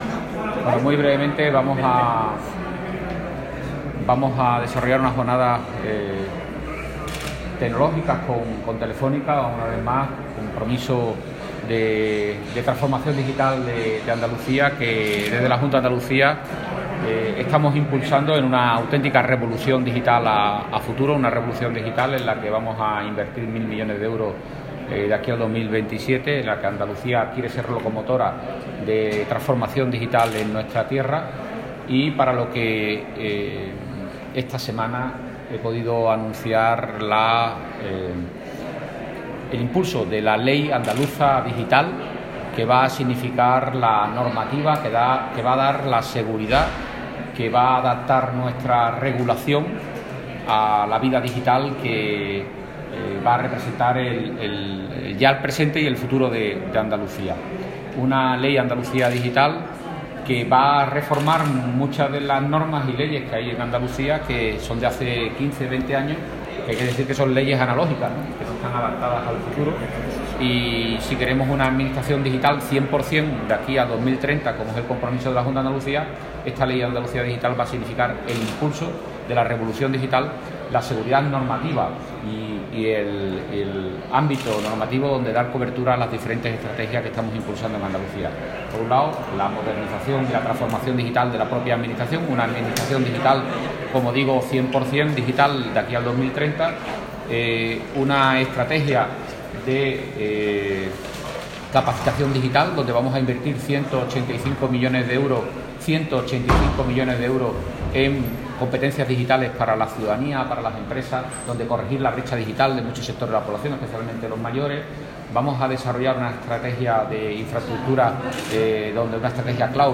El consejero de la Presidencia, Interior, Diálogo Social y Simplificación Administrativa, Antonio Sanz, ha anunciado este viernes durante su intervención en los Desayunos en Clave Digital que organizan Telefónica y San Telmo Business School en Málaga, que La Agencia Digital de Andalucía (ADA) ha publicado licitación de un Acuerdo Marco para la prestación de servicios de Consultoría, Oficina de Gobierno y Seguimiento de proyectos en materia de tecnología de la información y la comunicación por un valor estimado que ronda los 100 millones de euros.